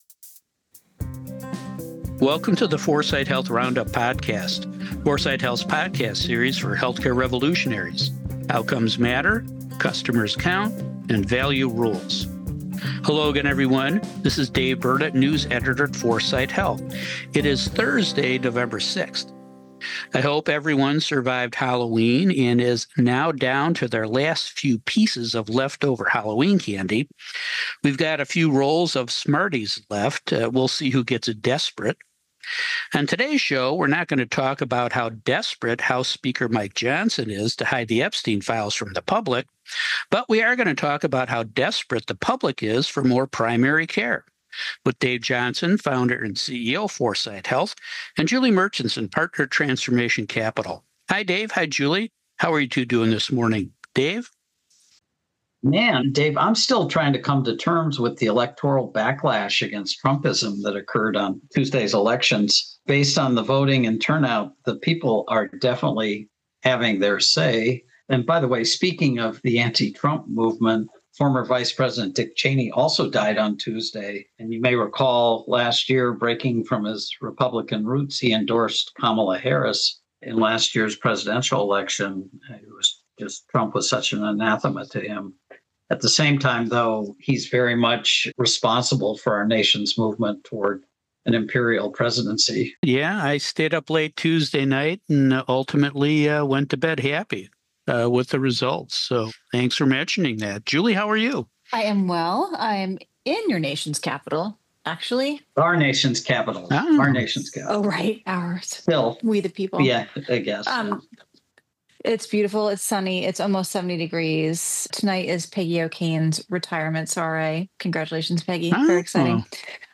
A new report says health systems want to expand their primary care footprint. We discussed their possible motives and whether patients will benefit on the new episode of the 4sight Health Roundup podcast.